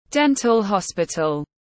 Bệnh viện răng hàm mặt tiếng anh gọi là dental hospital, phiên âm tiếng anh đọc là /ˈden.təl hɒs.pɪ.təl/.
Dental hospital /ˈden.təl hɒs.pɪ.təl/
Dental-hospital.mp3